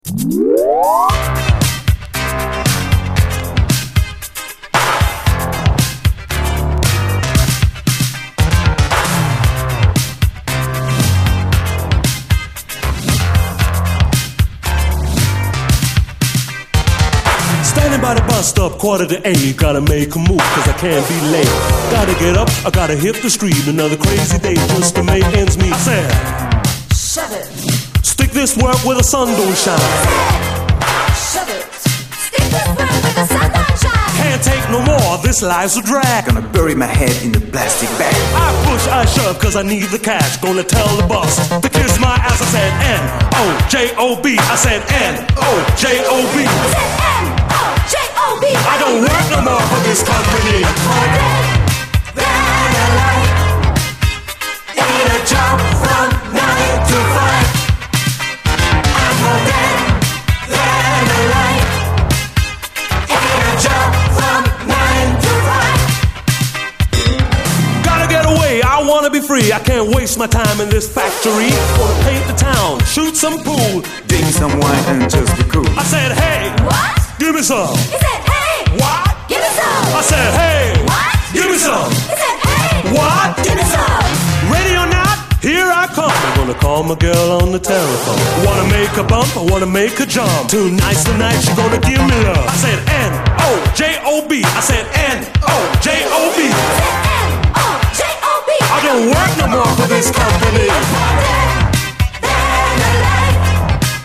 DISCO, HIPHOP
ドイツ産キャッチー・エレクトロ・ブギー！